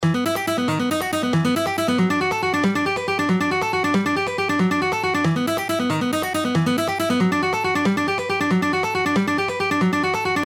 Alternate Picking Exercises 1
Here is exercise 1 in original Speed:
Alternate-Picking-Exercises-1.mp3